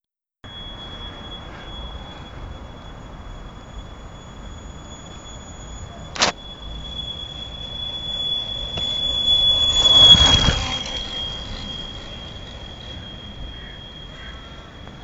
Dopplereffekt
Betrieben wird der Piezotöner konkret mit einer 9V Batterie.
Die Durchführung erfolgte auf der Straße vor meiner Wohnung.
Man erkennt gut den Peak bei rund 3.3 kHz:
Damit das Signal aber auch ausreichend stark ist, habe ich es noch schnell mit Audacity verstärkt:
Da der Ton bei rund 3.3 kHz liegt, wählte ich als Frequenzbereich das Intervall [1000 Hz, 4000 Hz] aus: